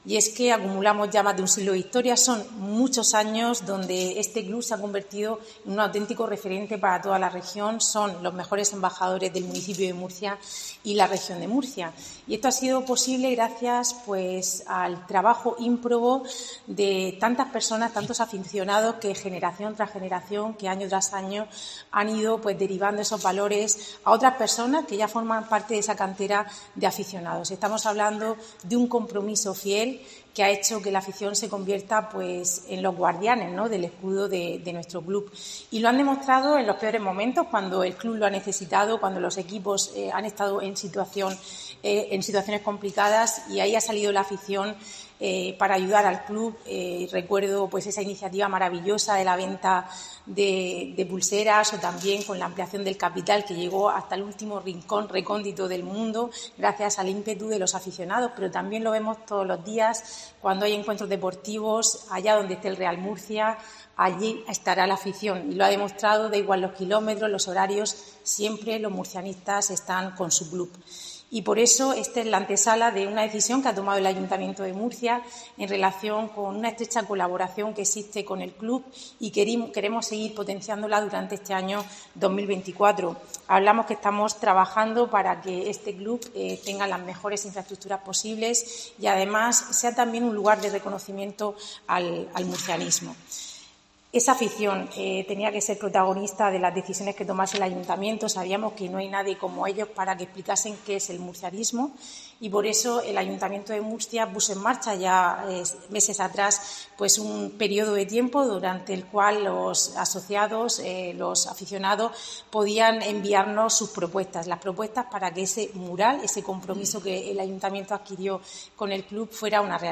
Rebeca Pérez, vicealcaldesa de Murcia